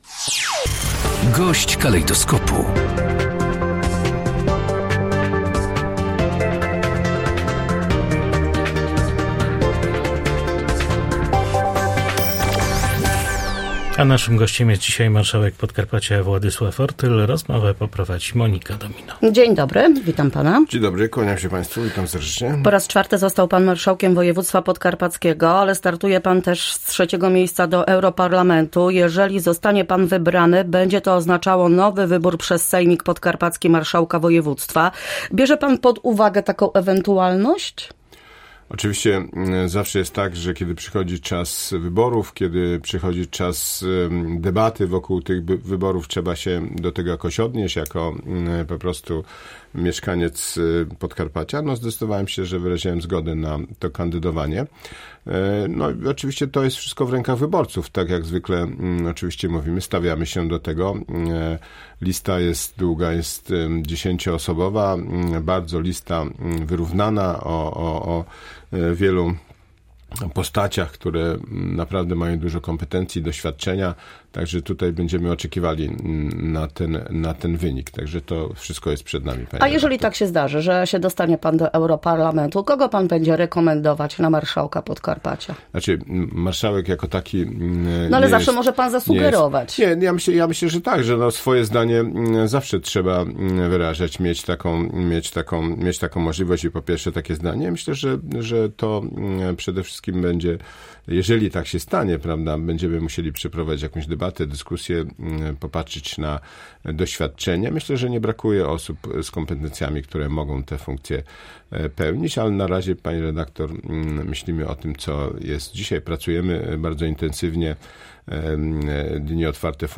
Gość Polskiego Radia Rzeszów, marszałek Ortyl po raz czwarty będzie pełnić tę funkcję.